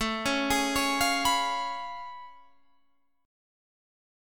A+M9 chord